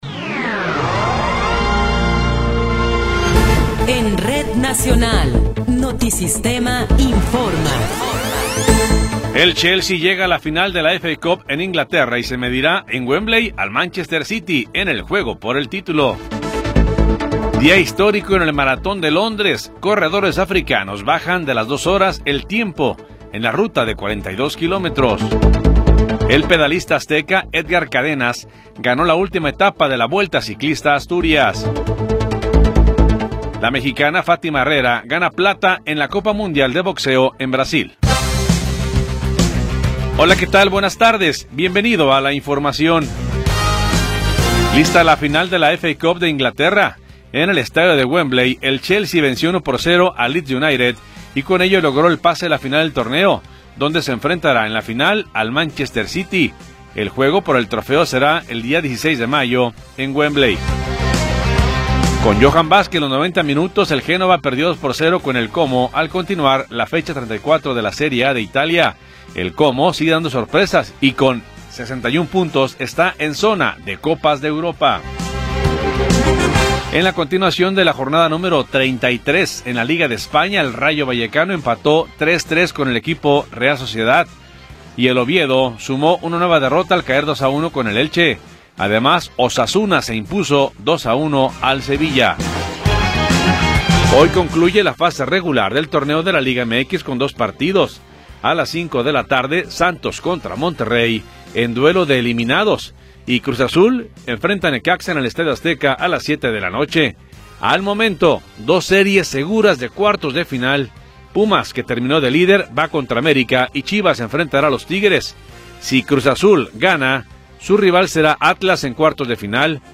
Noticiero 14 hrs. – 26 de Abril de 2026
Resumen informativo Notisistema, la mejor y más completa información cada hora en la hora.